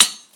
darkwatch/client/public/dice/sounds/surfaces/surface_metal4.mp3 at 8da9ac2cf6229fdb804e6bfaca987aa241ffc780
surface_metal4.mp3